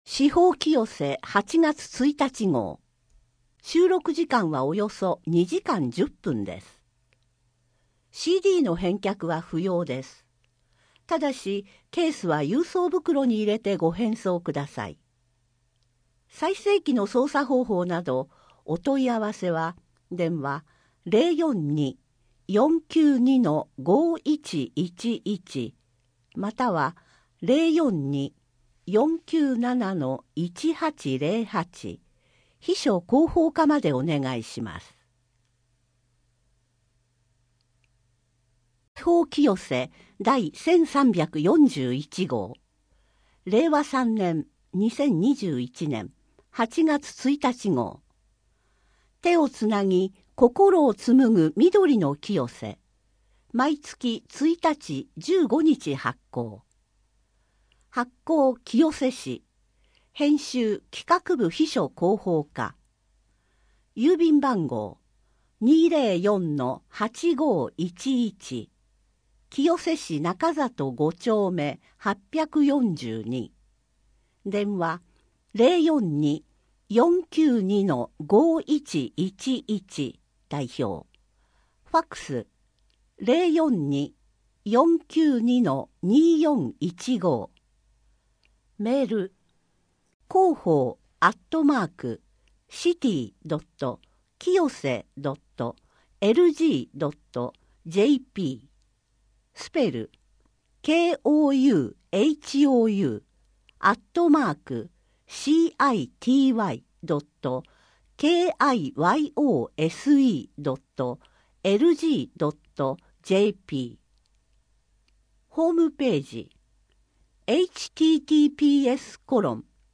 声の広報は清瀬市公共刊行物音訳機関が制作しています。